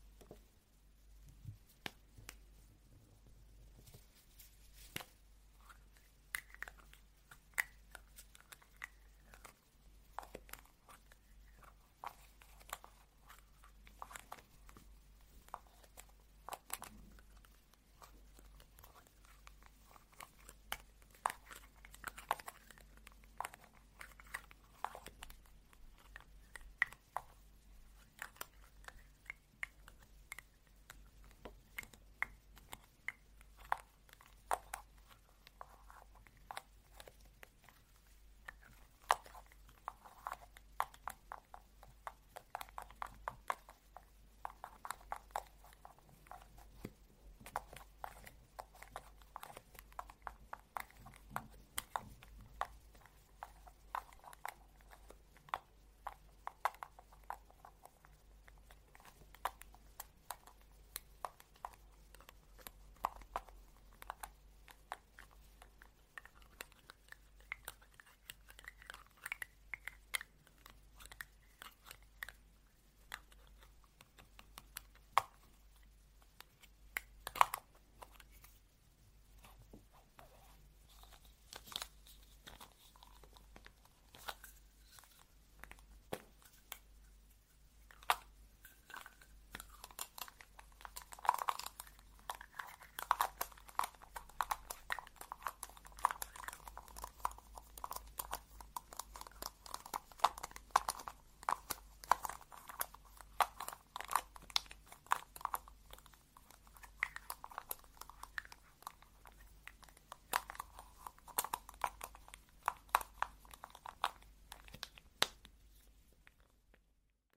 Tiếng Thỏi Son môi, Mở nắp, trang điểm….
Tiếng Liếm môi, Liếm khi đánh Son môi Tiếng Son môi, Đánh son, Tô son, trang điểm….
Thể loại: Tiếng động
Description: Âm thanh thỏi son môi, tiếng mở nắp, tiếng lách tách, click bật nắp, tiếng khóa vặn, tiếng chạm vỏ, âm thanh son, sfx son môi, nắp son bật, tiếng thao tác make-up, tiếng mở hộp là những hiệu ứng thường dùng khi edit video trang điểm giúp tăng tính chân thực...
tieng-thoi-son-moi-mo-nap-trang-diem-www_tiengdong_com.mp3